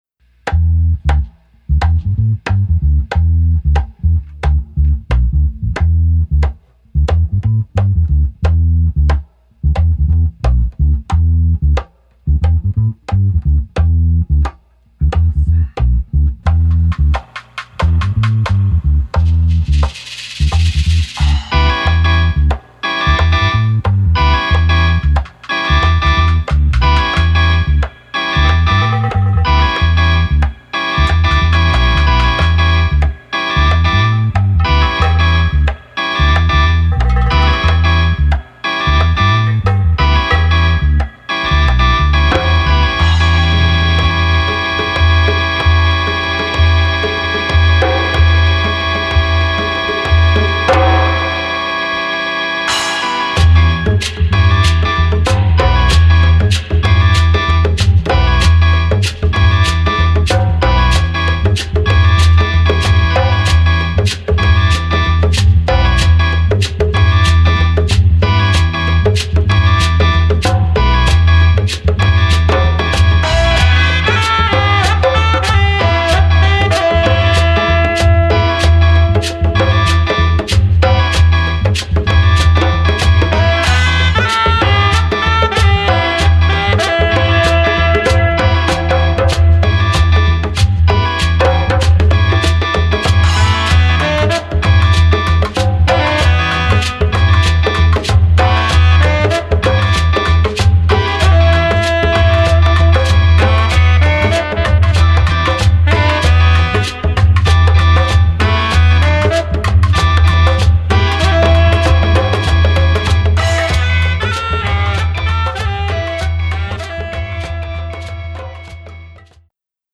Afro Beat , Hip Hop / R&B , House